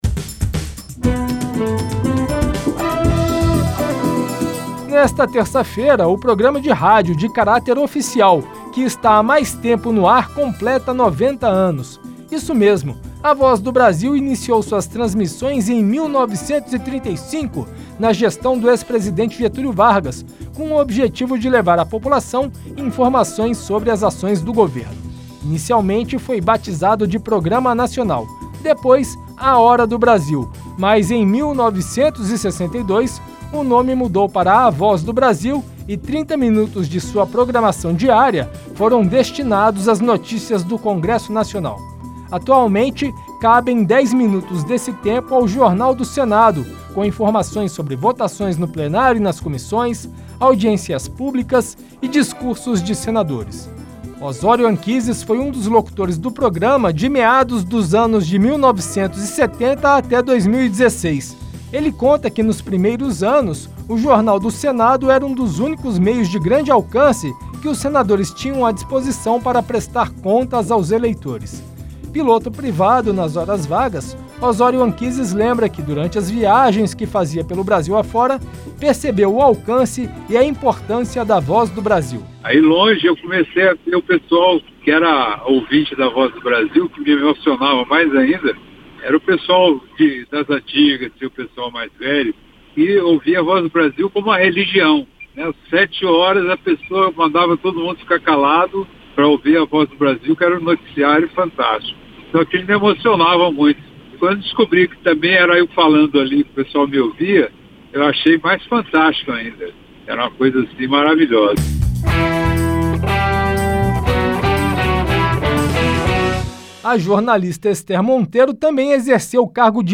Rádio Senado : Notícias.